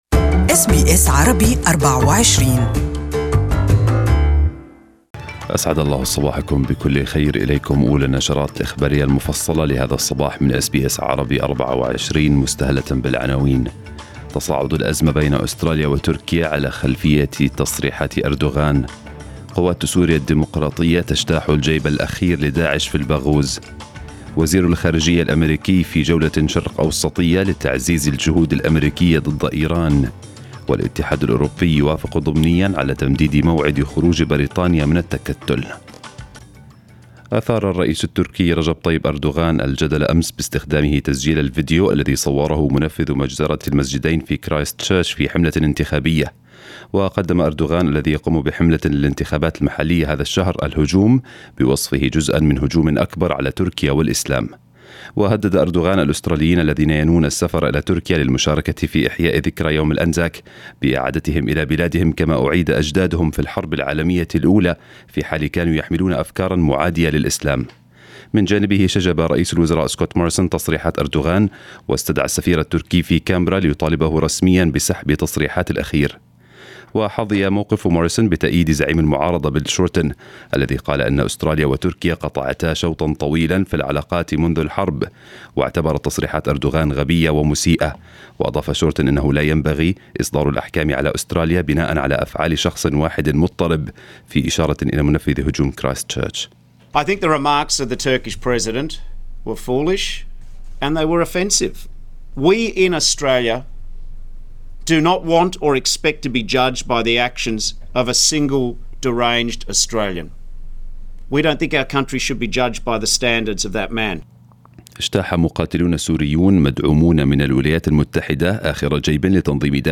News bulletin in Arabic for this morning